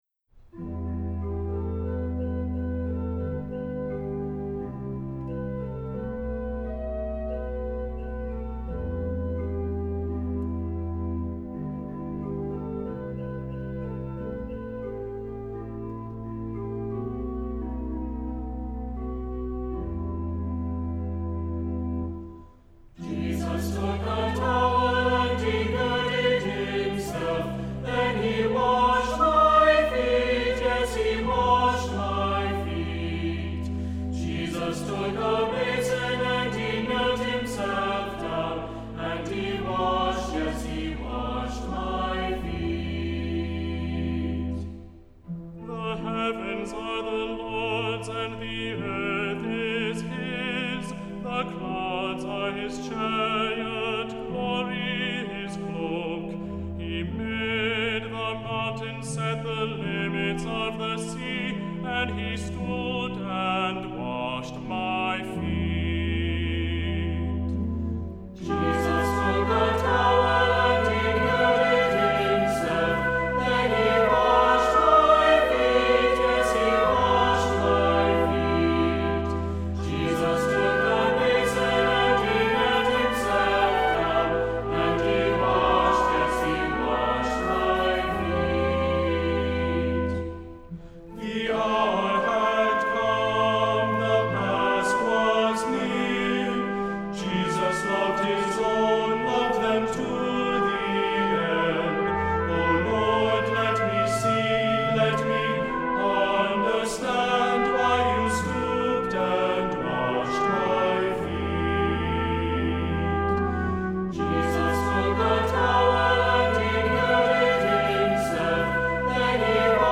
Voicing: Unison with descant; Cantor; Assembly